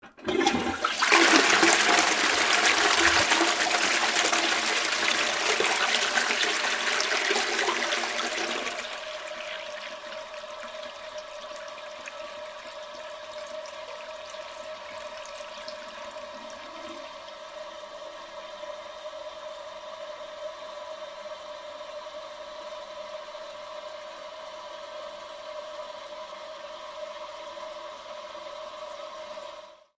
Шум воды в унитазе при смыве